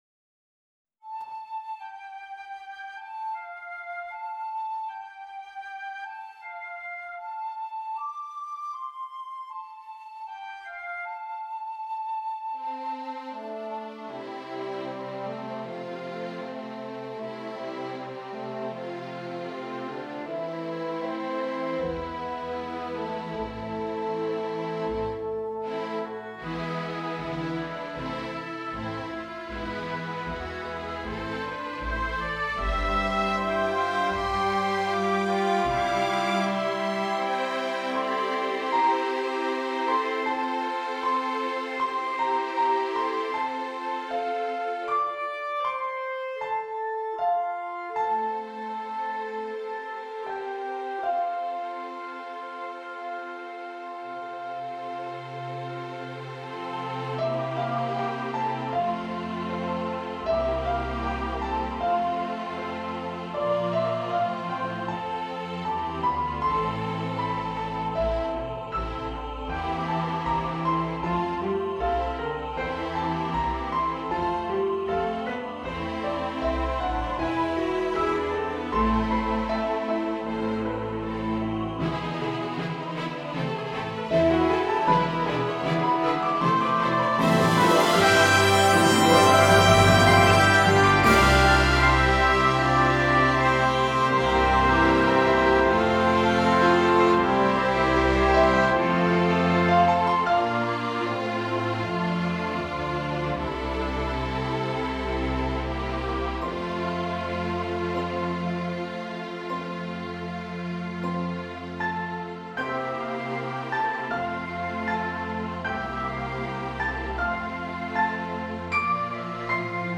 Solo voice (opt)
SATB
2 Flutes
Oboe
2 Clarinets
Bassoon
3 Horns
Tuba
Timpani
Piano
Harp
Cello